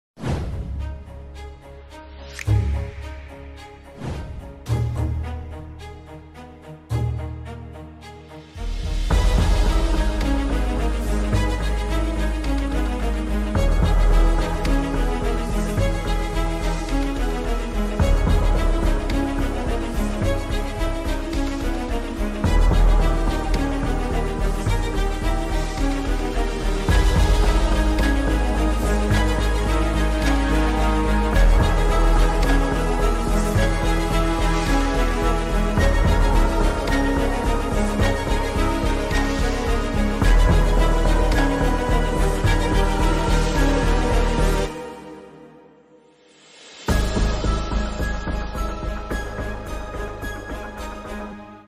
An original main title theme